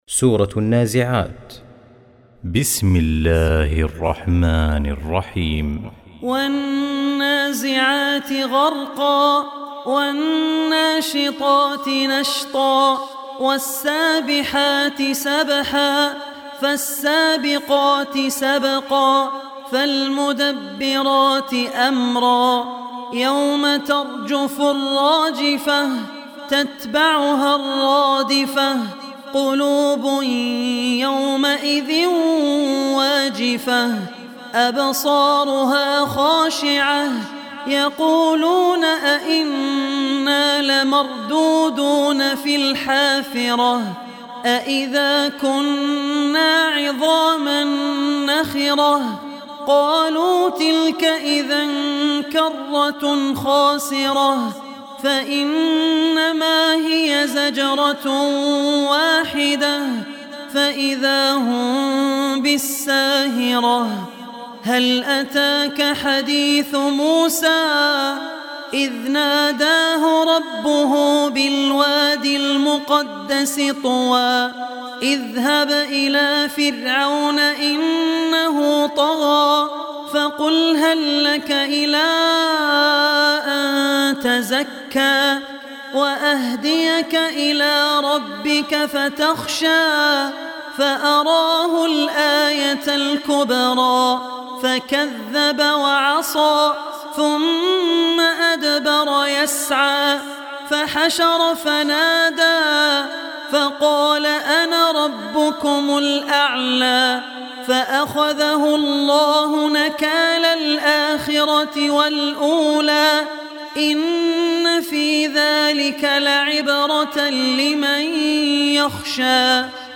Surah An Naziat Recitation by Abdur Rahman Ossi
Surah An Naziat, listen online mp3 tilawat / recitation in Arabic in the beautiful voice of Sheikh Abdul Rehman Al Ossi.